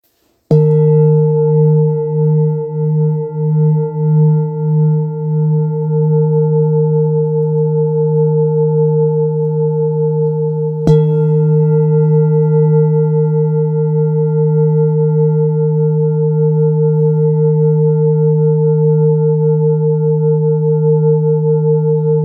Full Moon Bowl, Buddhist Hand Beaten, Moon Carved, Antique Finishing
Material Seven Bronze Metal
This is a Himalayas handmade full moon singing bowl. The full moon bowl is used in meditation for healing and relaxation sound therapy.